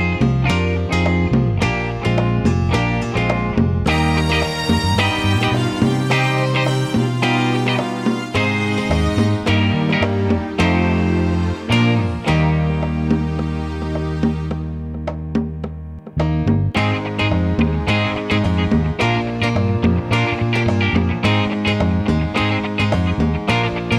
no drum kit or Backing Vocals Disco 3:04 Buy £1.50